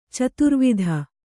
♪ caturvidha